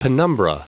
Prononciation du mot penumbra en anglais (fichier audio)
Prononciation du mot : penumbra